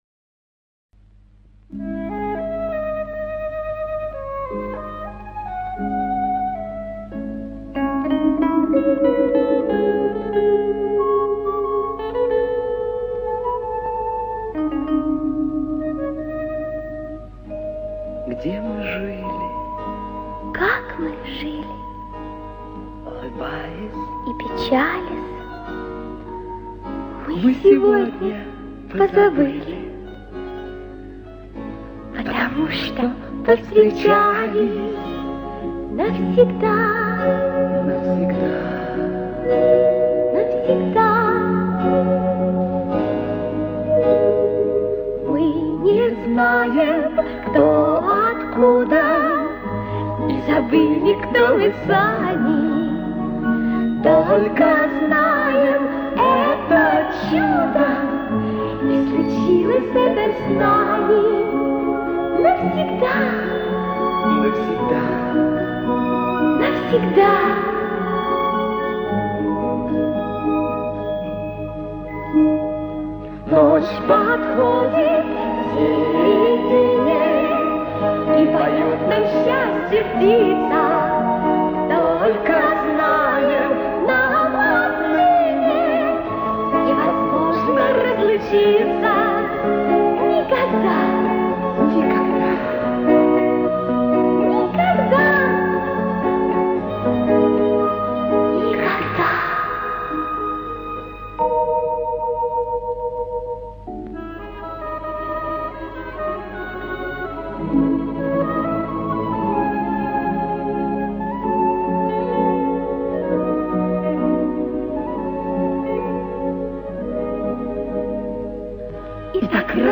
Genre: sayndtrek